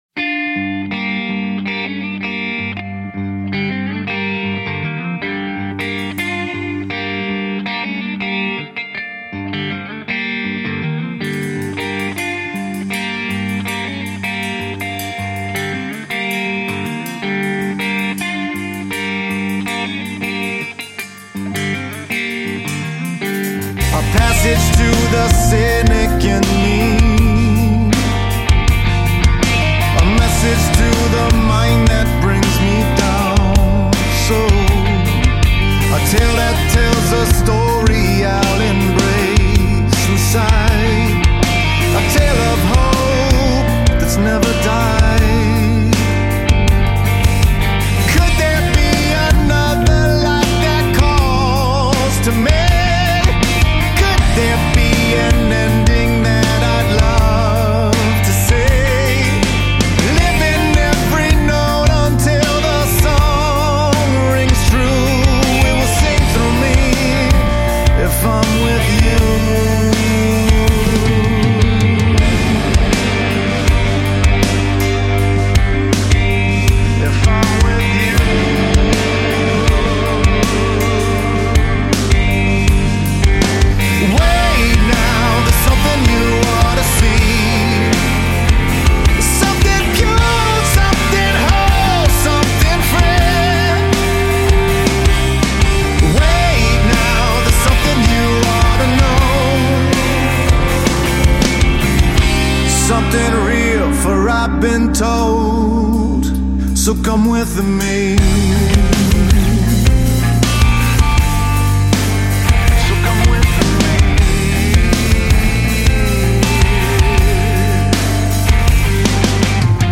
Для любителей мелодичного хард рока и AORщиков
Очень пафосно, с гитарными запилами, но в меру.